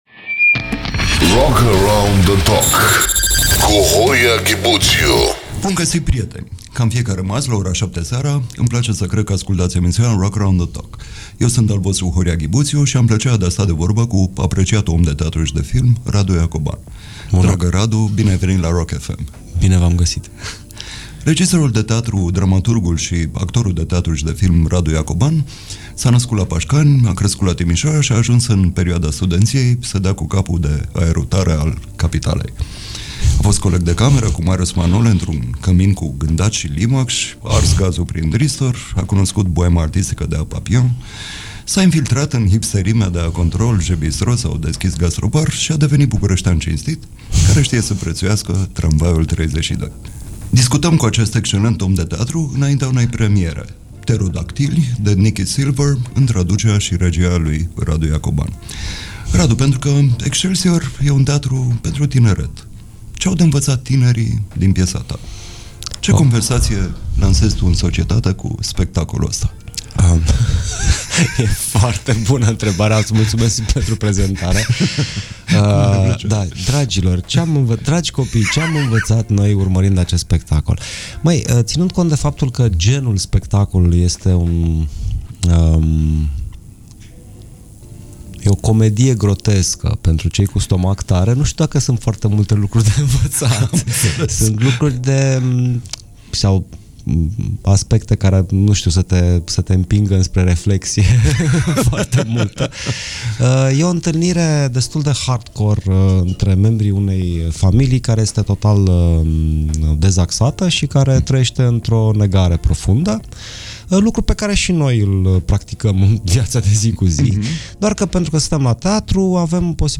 Întrebări meșteșugite și răspunsuri cumpănite, cu câte un invitat distins și un jurnalist destins.